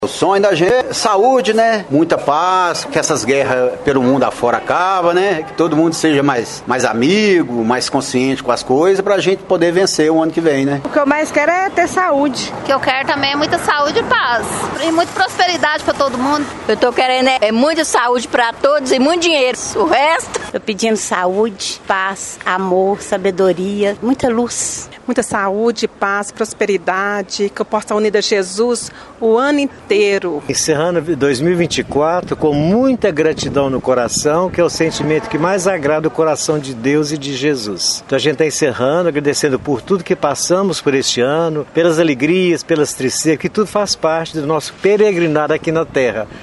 O Jornal da Manhã foi às ruas para saber quais são as expectativas dos paraminenses para 2025. Os desejos de sucesso e realização dos objetivos são mais comentados entre a população.